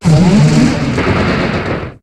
Cri de Fulguris dans Pokémon HOME.